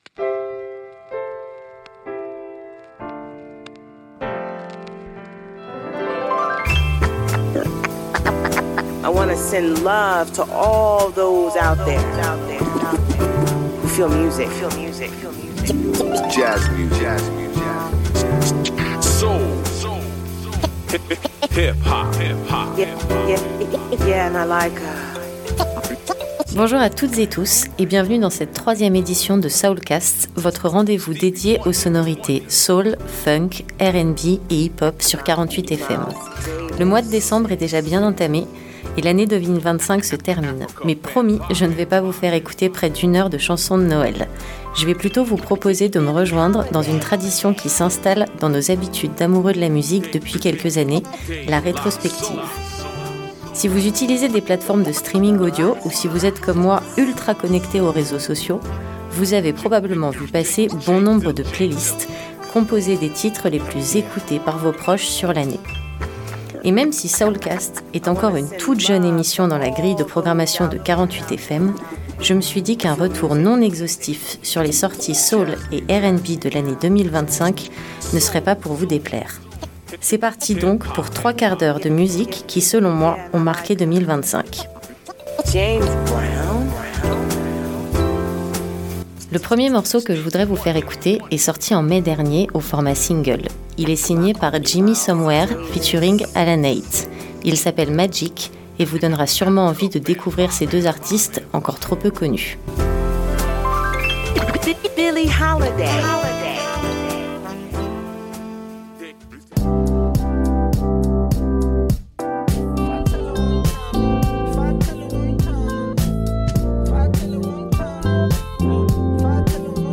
Emission du mardi 16 décembre à 21 h 00